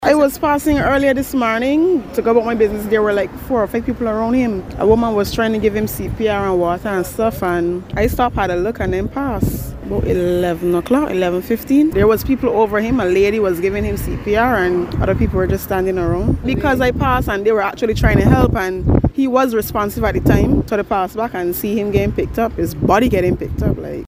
A shocked passerby explained what she saw.
An onlooker at the scene at Massy carpark in Warrens.